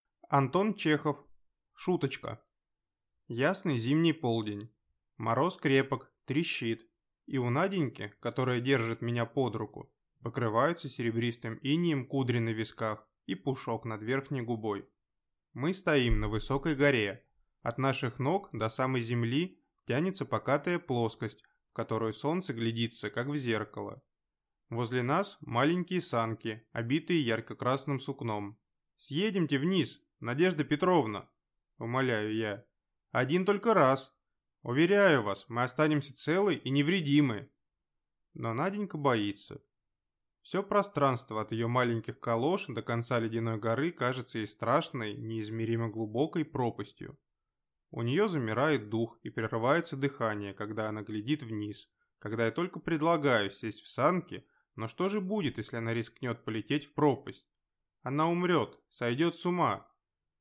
Аудиокнига Шуточка | Библиотека аудиокниг